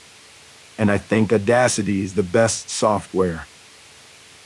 If that’s accurate, the signal is only ~20dB louder than the noise-floor, that would sound like this …